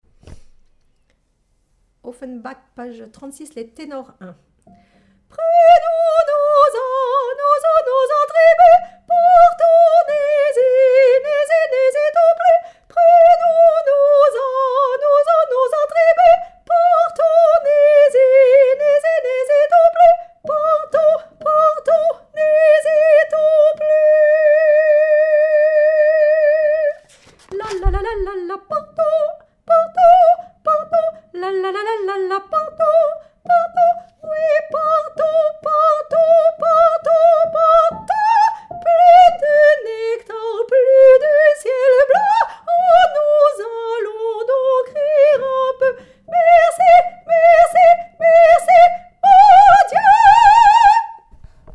gloire2_Tenor1.mp3